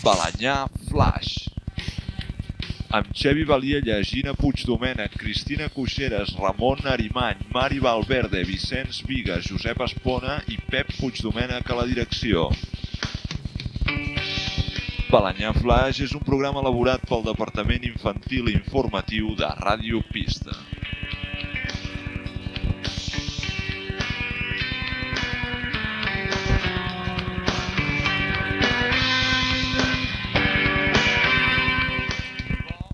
Presentació del programa amb els noms de l'equip Gènere radiofònic Infantil-juvenil